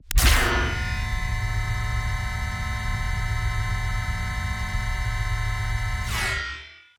SCIEnrg_Shield Activate Deactivate_02.wav